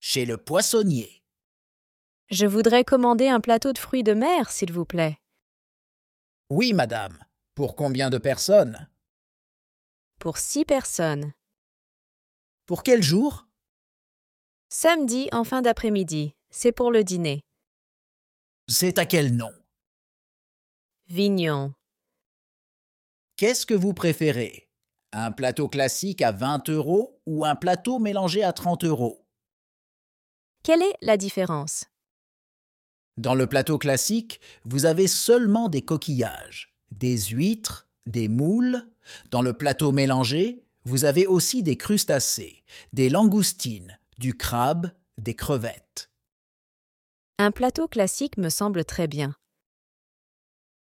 Dialogue en français - Chez le poissonnier
Dialogue-en-francais-Chez-le-poissonnier.mp3